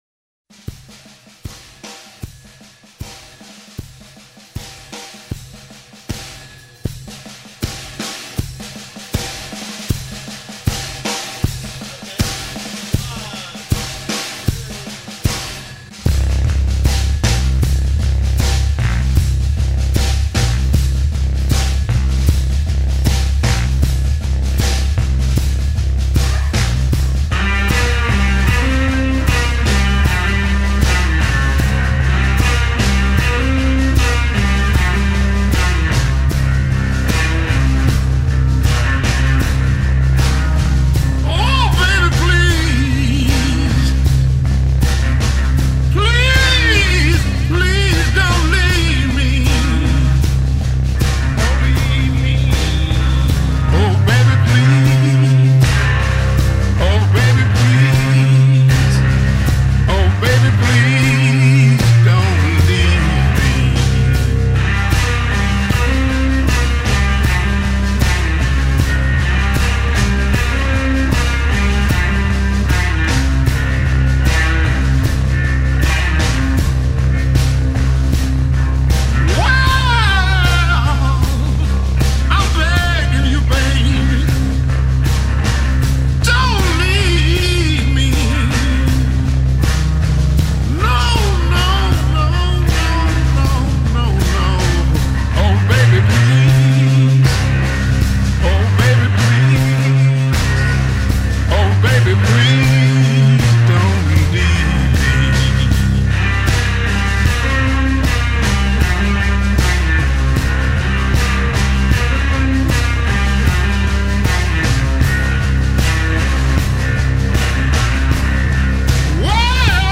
شیکاگو بلوز